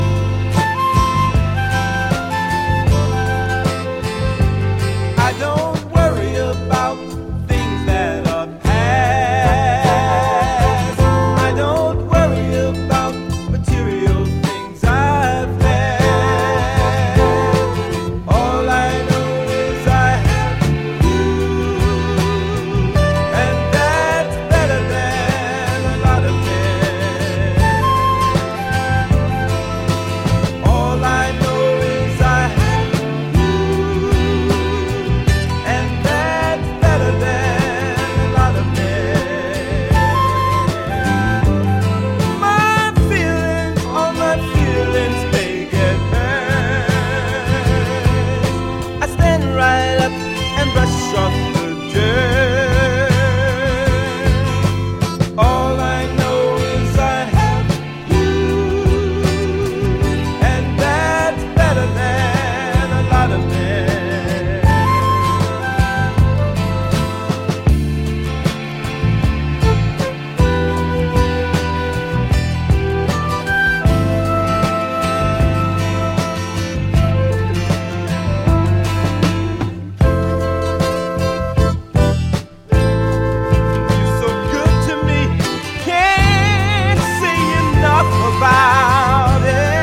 Legitimate repress of this long lost Soul / Funk classic.
Funk / soul